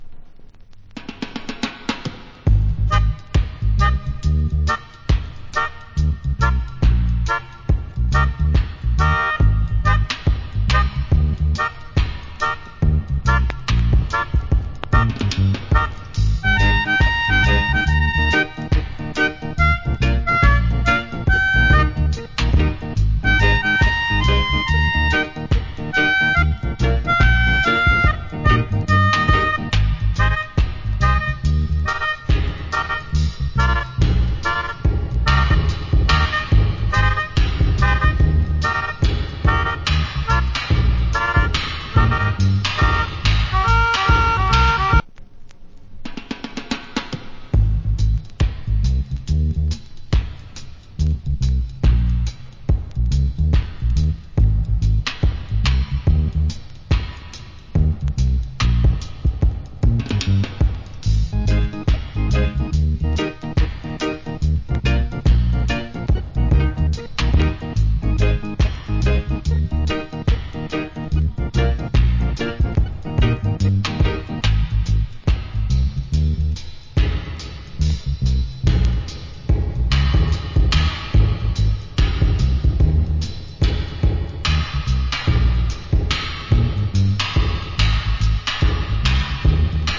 Nice Melodica Inst.